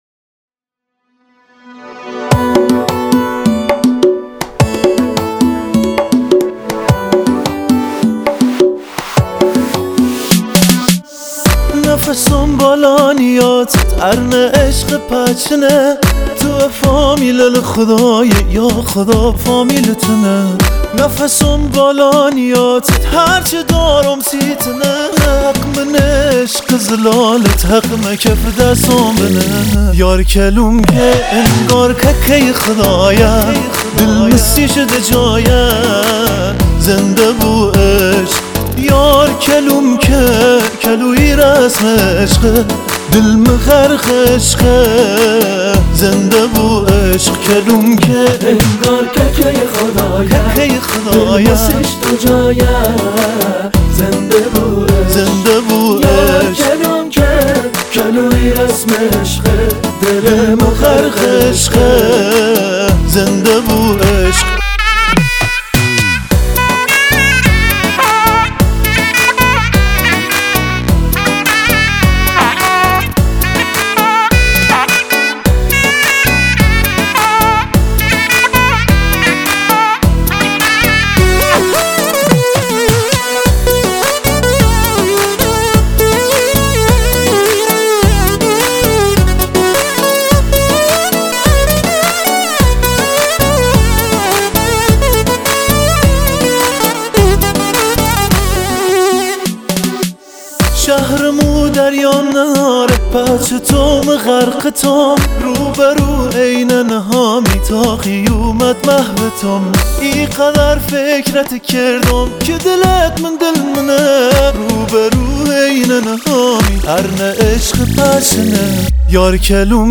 نوازنده کرنا